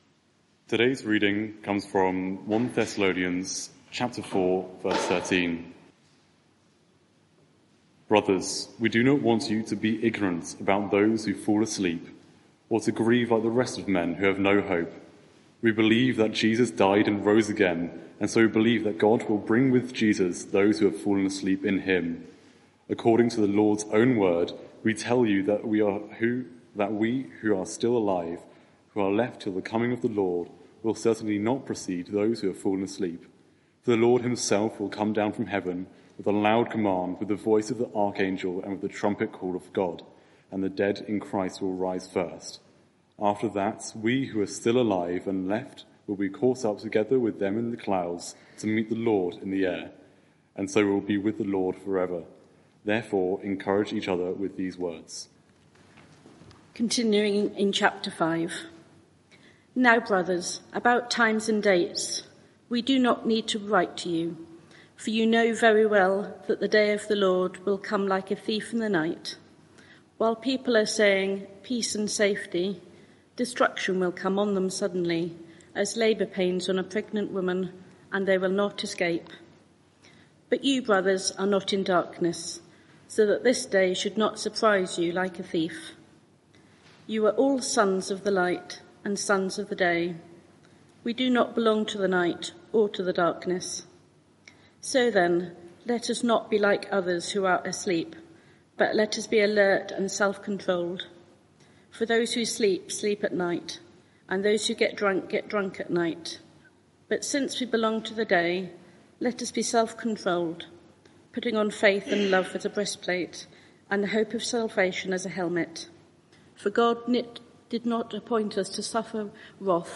Media for 6:30pm Service on Sun 27th Aug 2023 18:30
Theme: 1 Thessalonians 4:13-5:11 Sermon (audio)